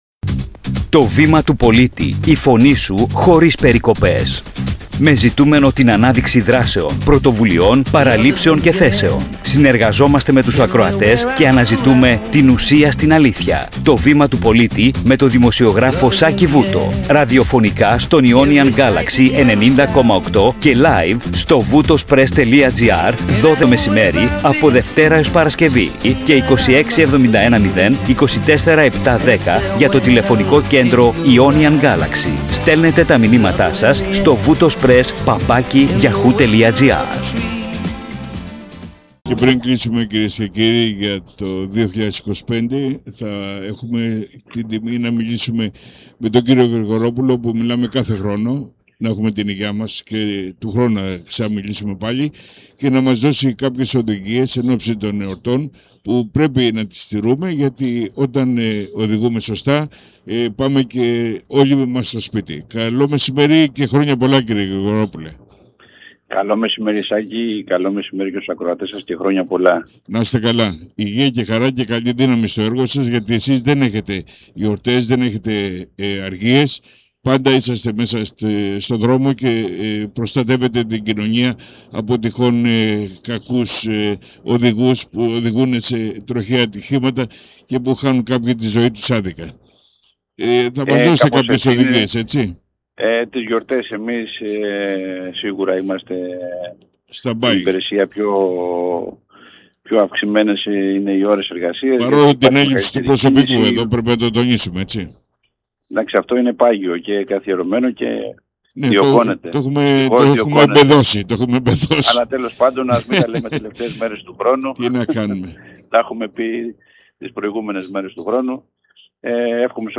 Ραδιοφωνικά στον Ionian Galaxy 90.8